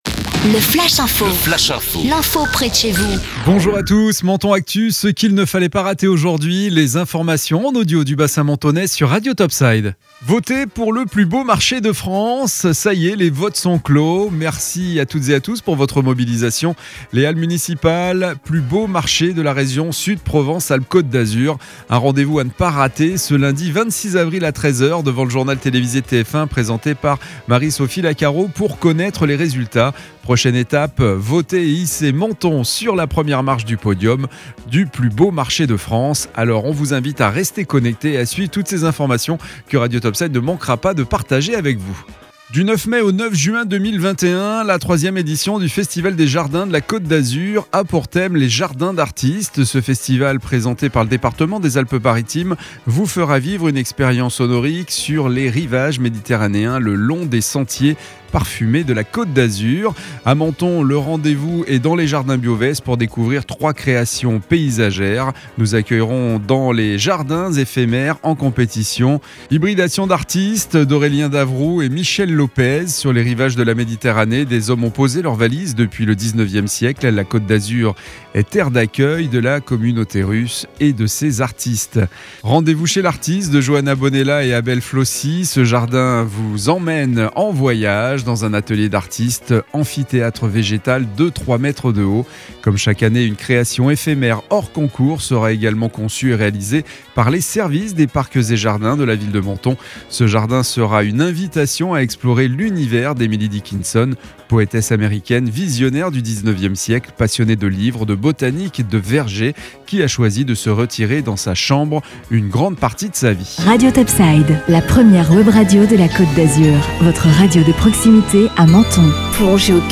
Menton Actu - Le flash info du lundi 21 avril 2021